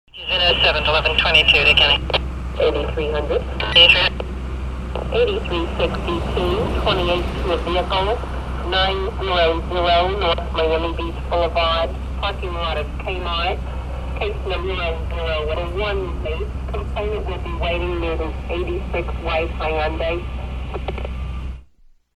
Категория: Разные звуки